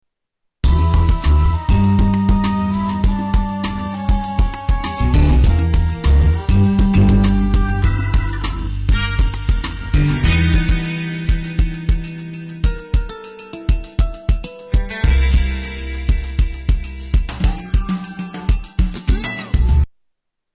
Stealth Music